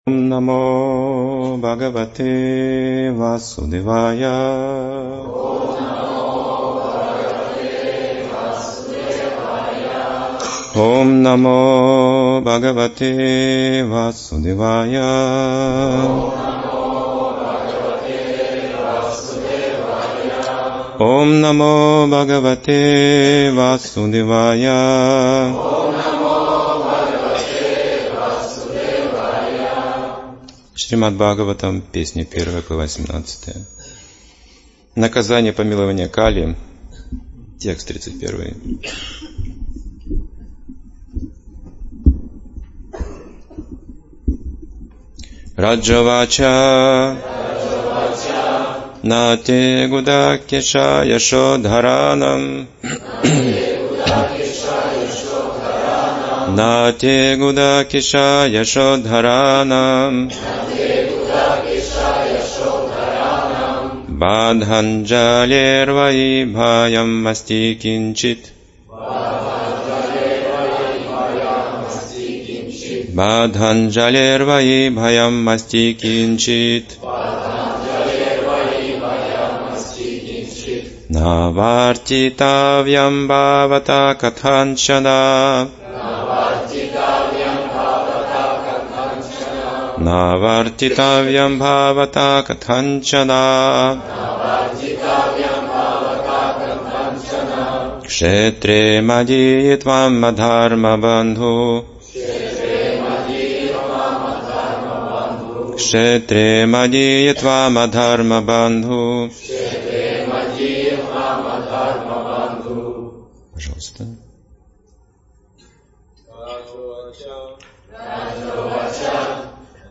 Темы, затронутые в лекции: Полное и неполное знание Наука взаимоотношений с Богом, миром и человеком Вайшнав - это тот, кто ведет себя как вайшнав Все в этом мире можно делать из сострадания и милости Майя ничего не прощает Человек не очистившийся не должен претендовать на власть Если царь мошенник - подданные ведут себя также Три Харидаса, которые особенно дороги Господу Милость Господа Чайтаньи Освобождение из тюрьмы